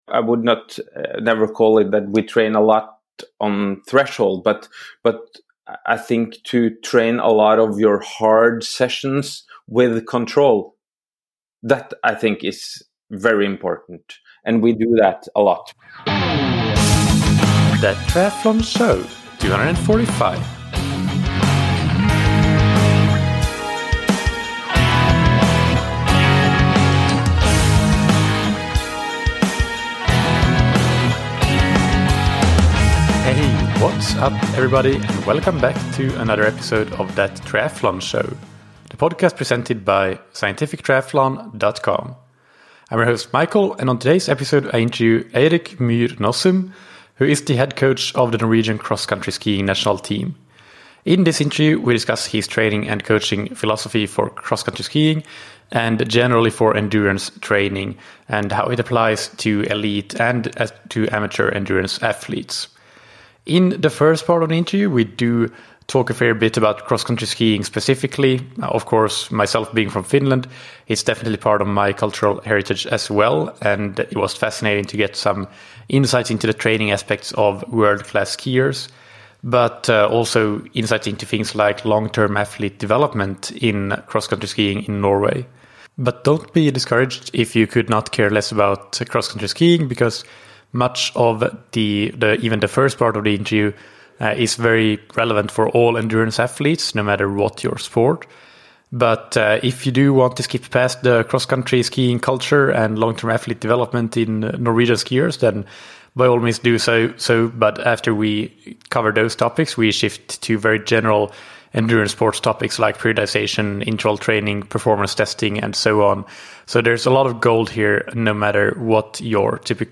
In this interview we discuss his training and coaching philosophy for cross-country skiing and generally for endurance training, and how it applies to elite and amateur endurance athletes, respectively.